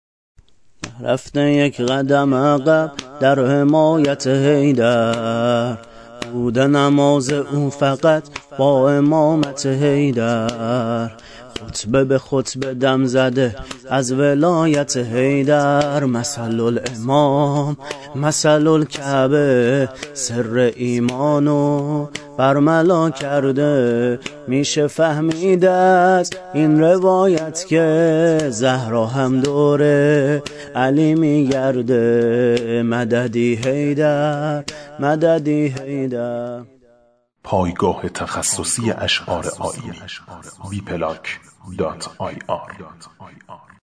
شور ، زمینه